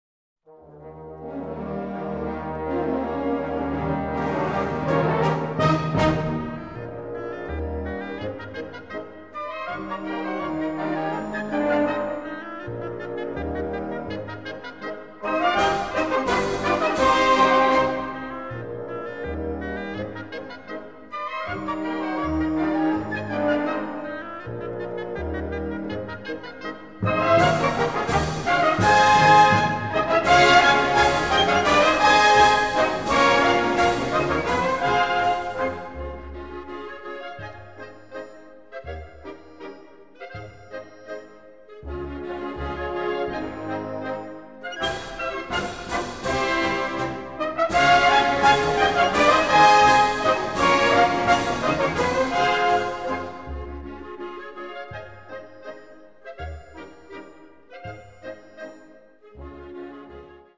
Kategorie Blasorchester/HaFaBra
Unterkategorie Polka
Besetzung Ha (Blasorchester)